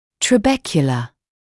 [trə’bekjələ][трэ’бэкйэлэ]трабекула, перекладина (мн. ч. trabeculae [trə’bekjəliː])